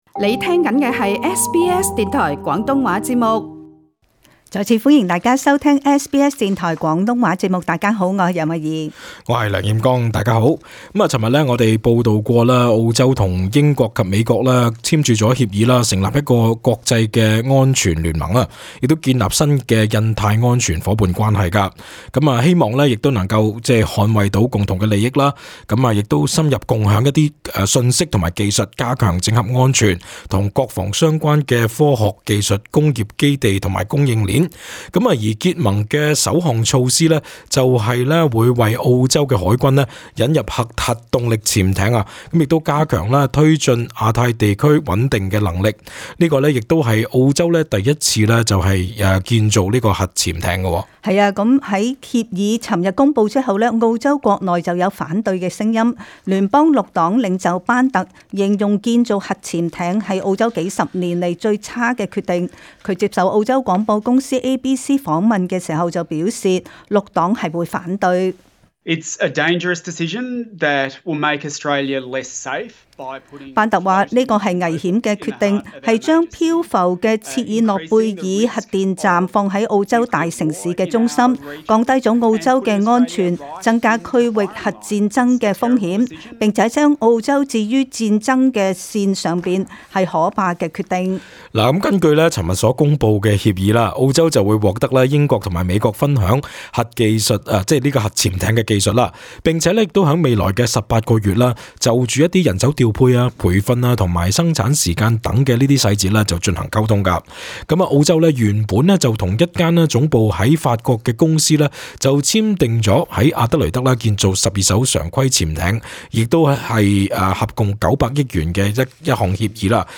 【時事報道】